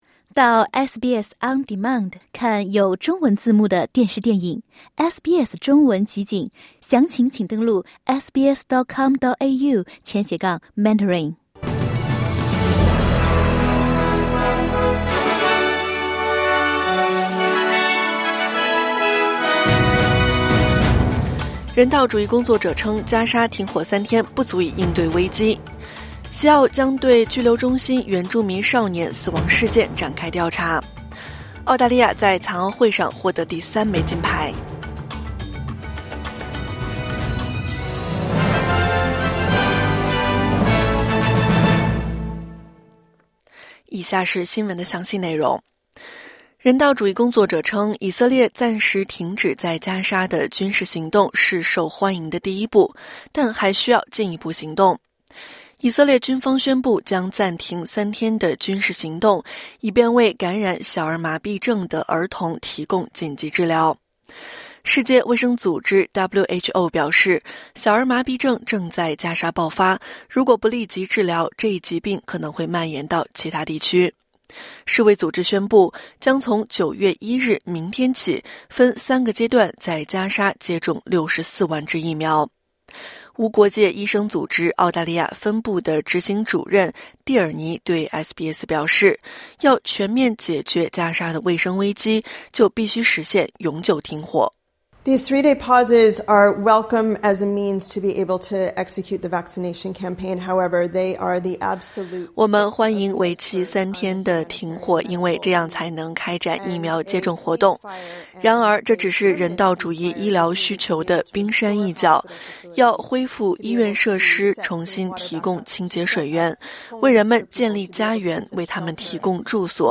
SBS早新闻（2024年8月31日）